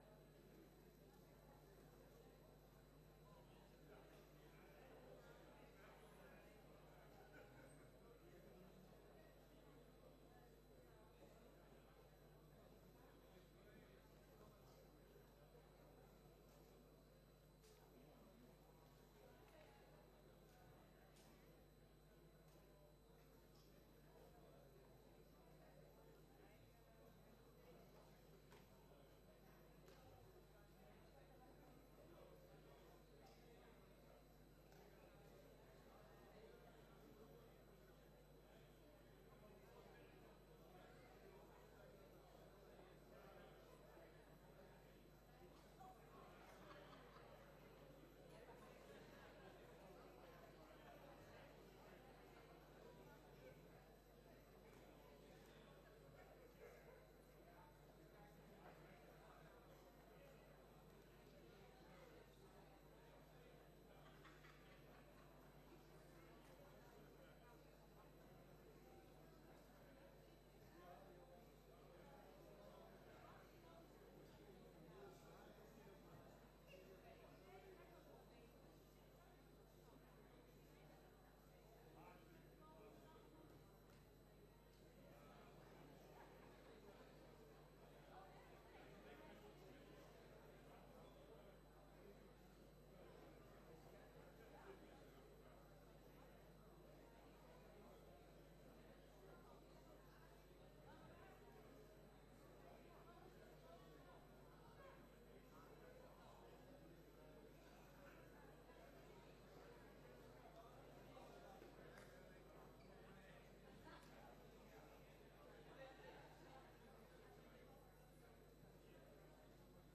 Locatie: Statenzaal